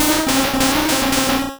Cri de Noadkoko dans Pokémon Rouge et Bleu.